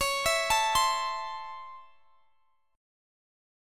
Listen to C#mM7#5 strummed